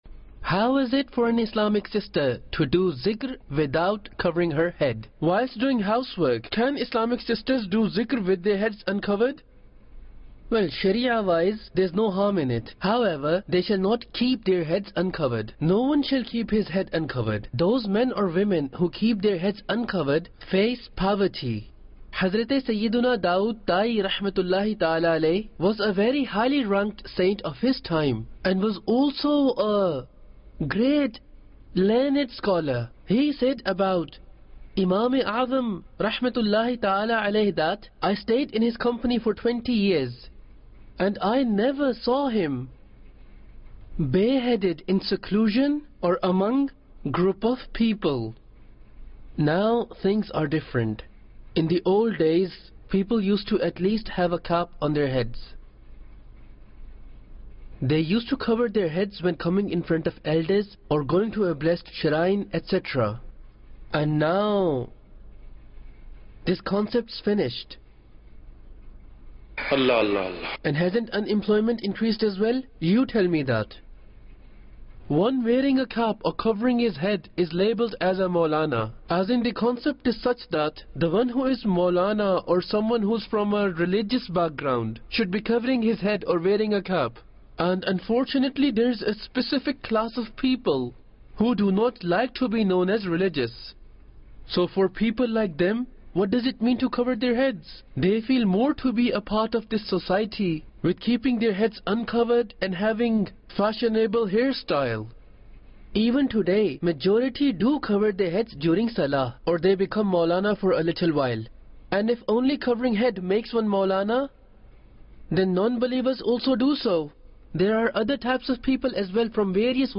What's The Rulling On An Islamic Sistr Reciting Zikr And Durood Without Dupatta? - English Dubbing Jul 2, 2021 MP3 MP4 MP3 Share What's The Rulling On An Islamic Sistr Reciting Zikr And Durood Without Dupatta?